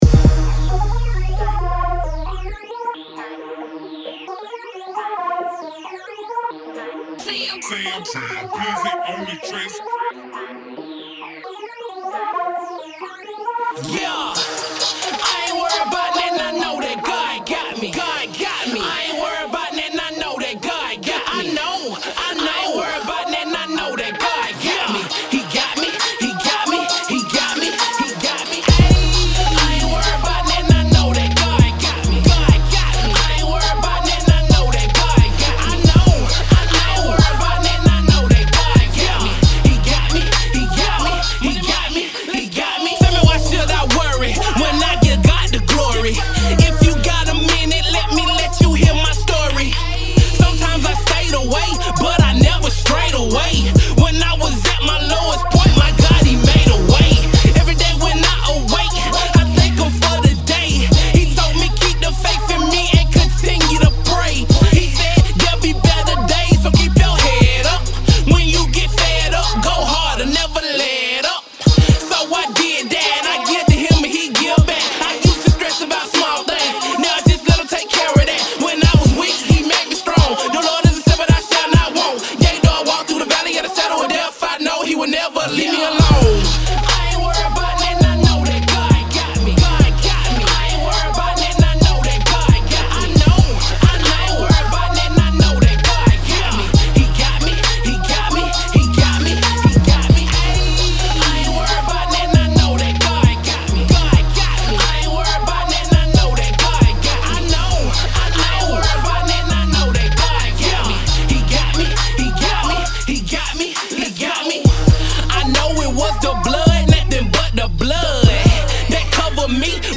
Christian/Gospel